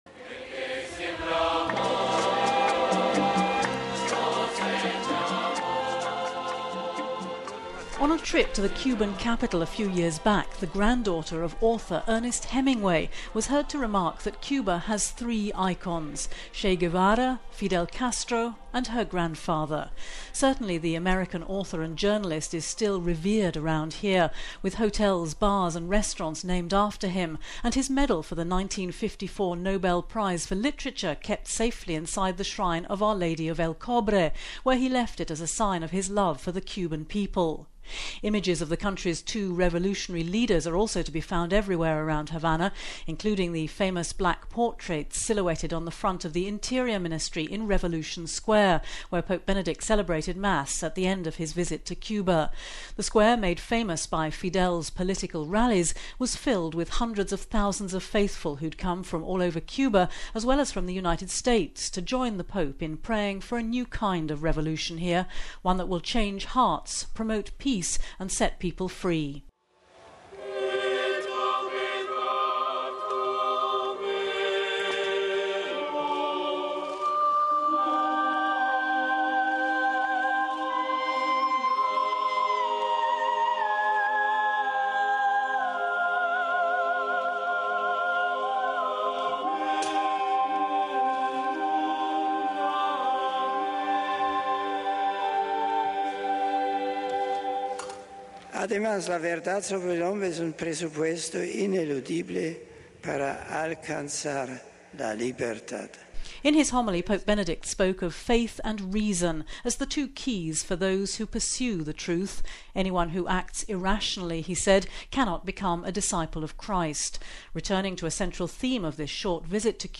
An estimated 300 thousand Cuban faithful filled Havana’s Revolution Square for an open air Mass presided over by Pope Benedict XVI on the final day of his visit to the Americas.